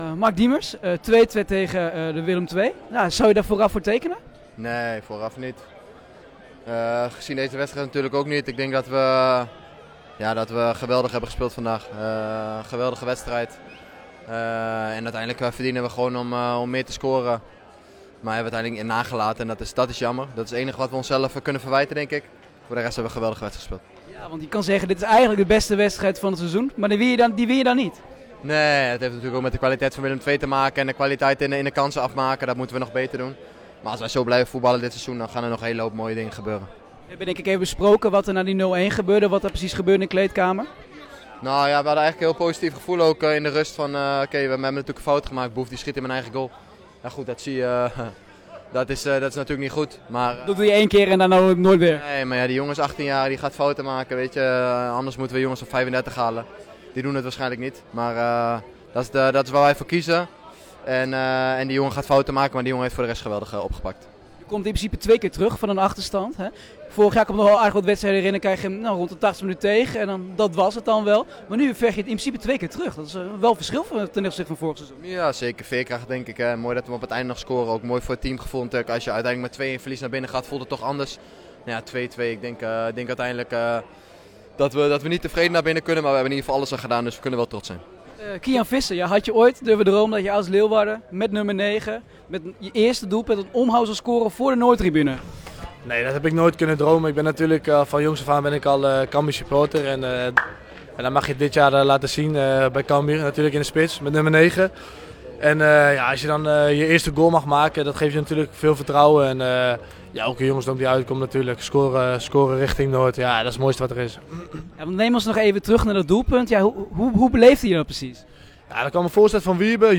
Na een rollercoaster van een wedstrijd sprak onze verslaggever met de hoofdrolspelers